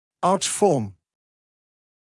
[ɑːʧ fɔːm][аːч фоːм]форма зубной дуги, форма зубного ряда